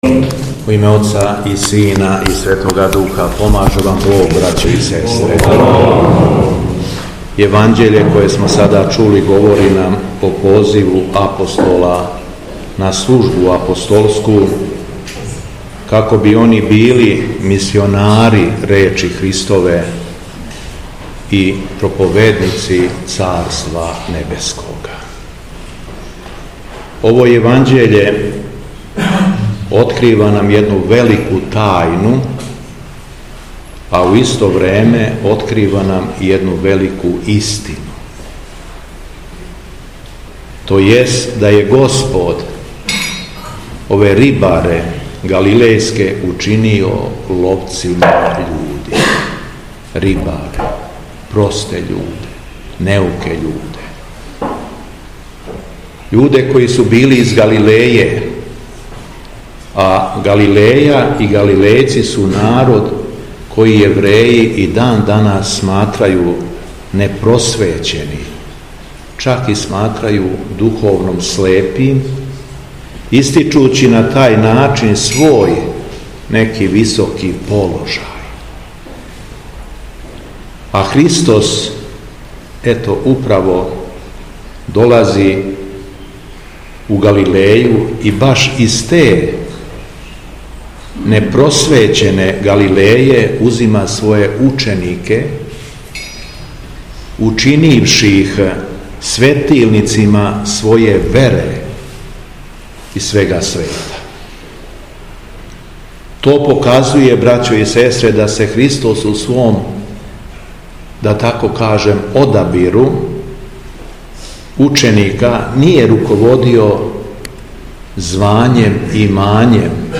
Беседа Његовог Високопреосвештенства Митрополита шумадијског г. Јована
Након прочитаног зачала из Светог Јеванђеља Високопреосвећени Владика обратио се сабраном народу рекавши: